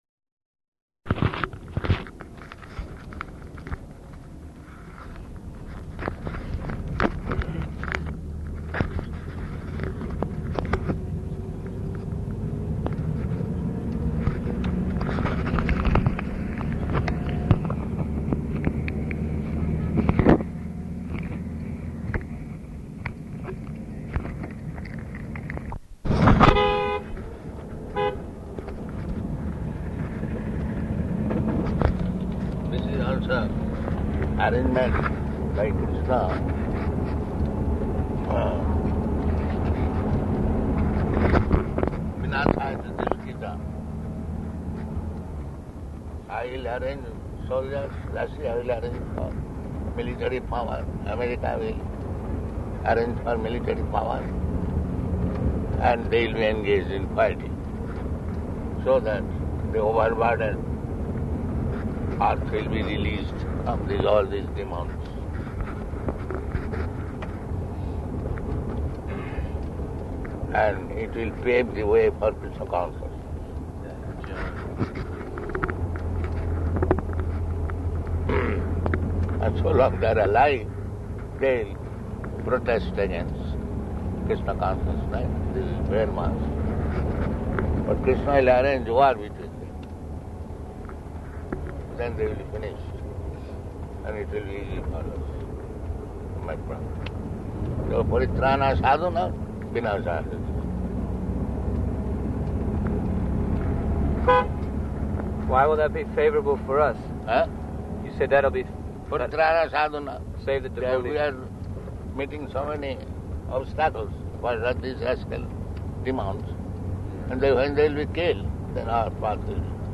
Morning Walk
Type: Walk
Location: Bhubaneswar